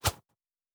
Whoosh 06.wav